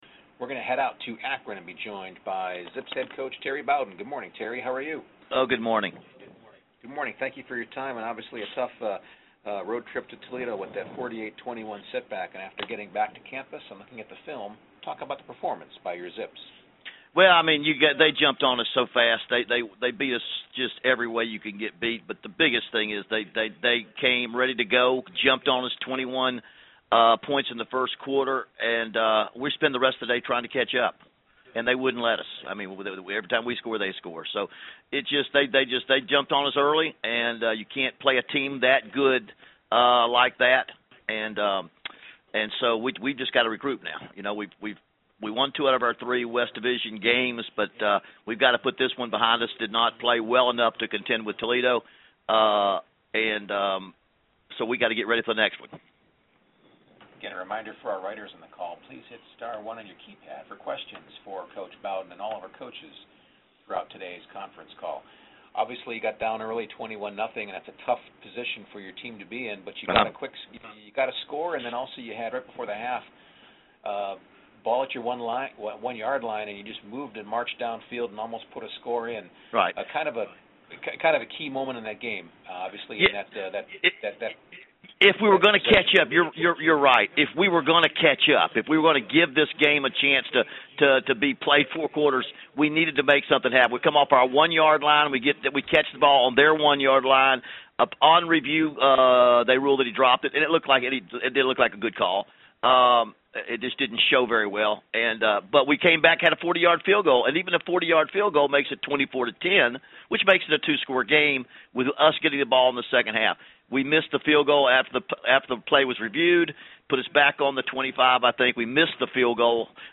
MAC Weekly Media Teleconference Audio